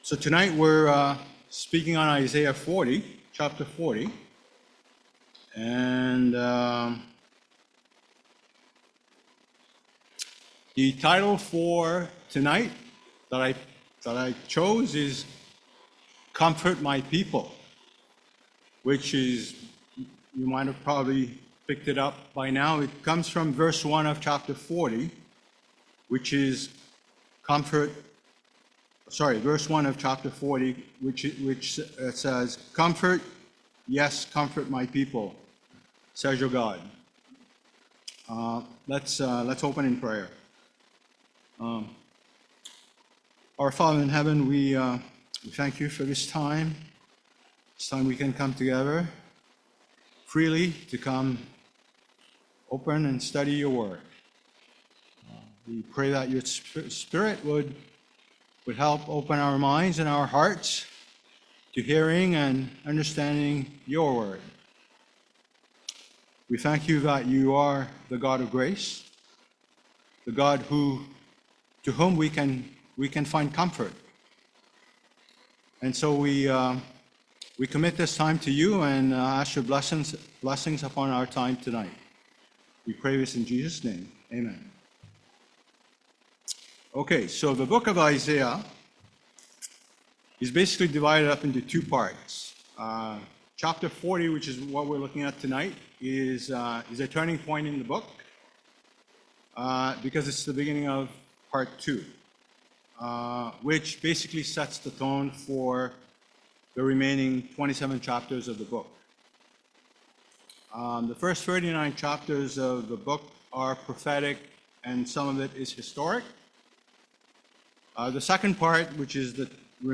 Sermons Archive - Rideauview Bible Chapel podcast
We are a non-denominational local Christian church located in Ottawa, Ontario Canada.